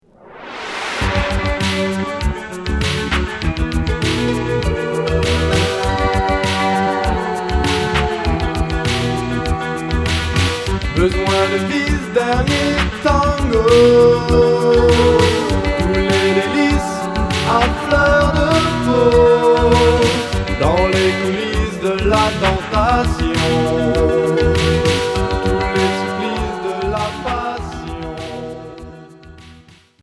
New wave Unique 45t retour à l'accueil